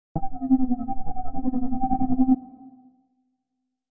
Descarga de Sonidos mp3 Gratis: miedo 25.